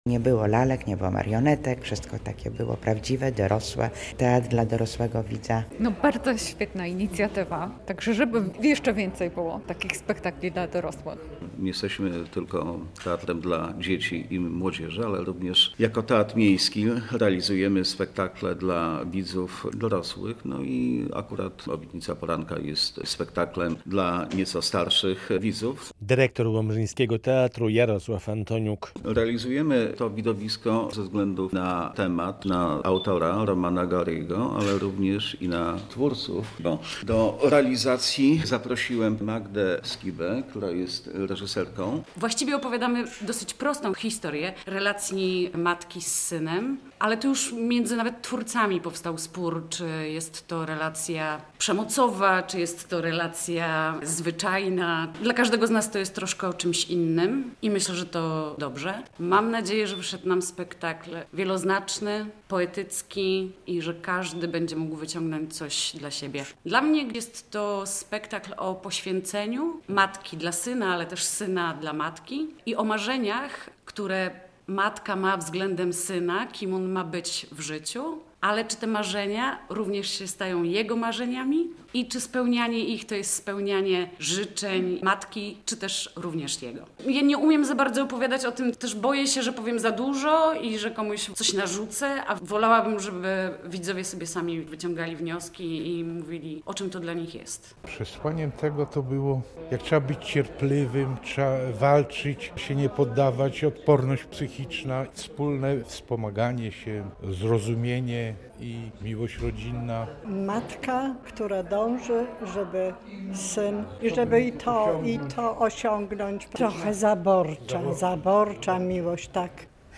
"Obietnica poranka" - premiera w Teatrze Lalki i Aktora w Łomży - relacja